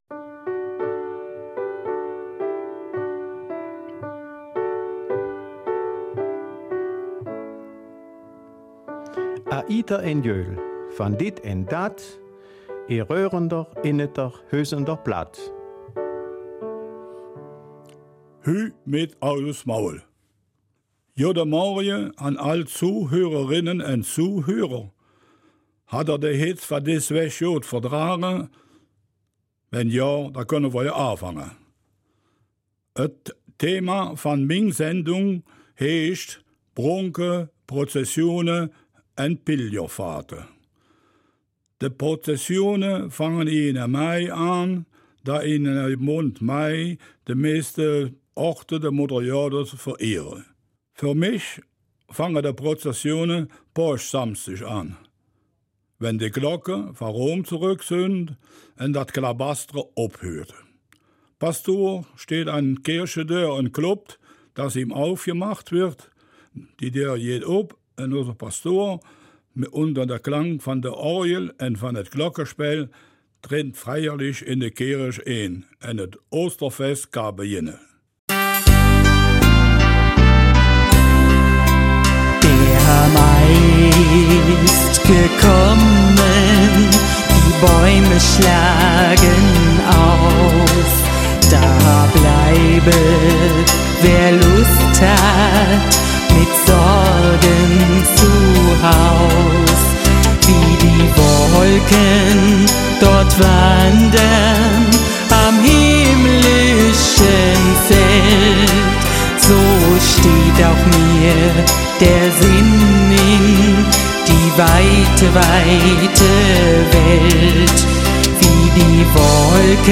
Raerener Mundart: Bronk, Prozession und Pilgerfahrten